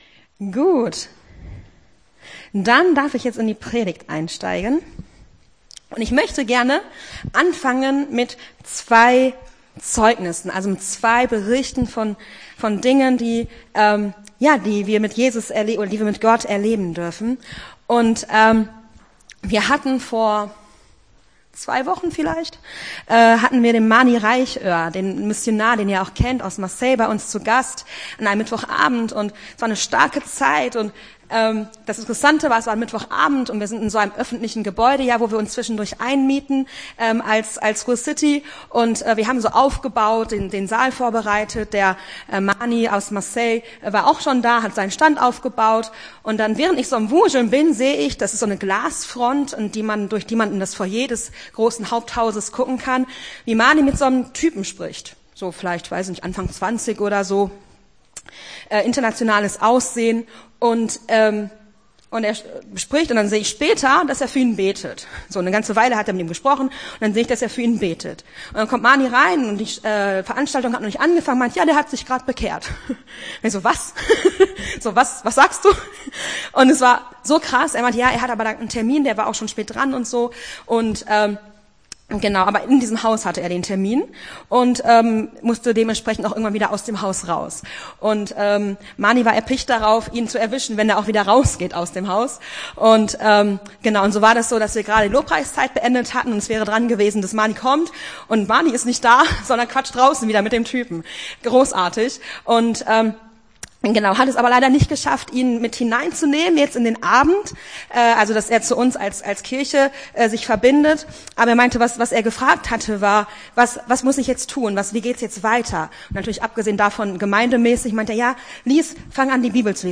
Gottesdienst 18.02.24 - FCG Hagen